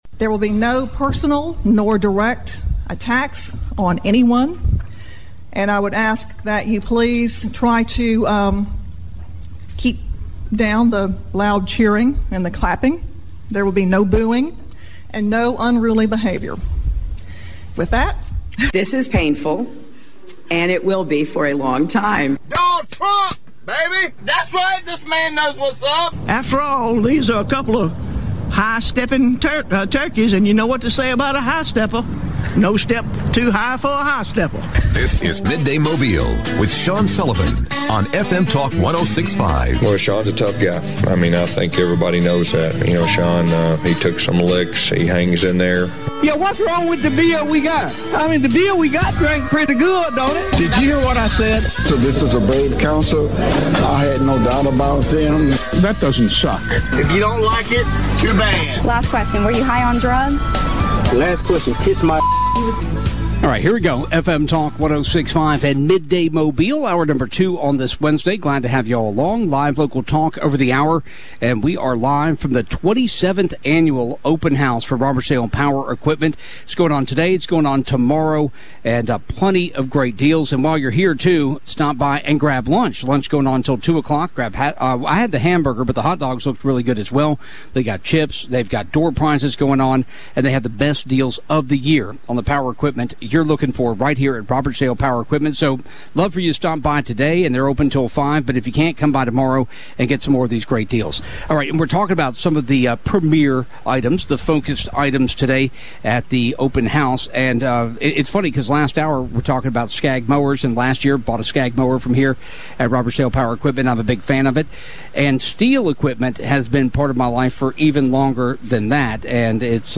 Live from Robertsdale Power Equipment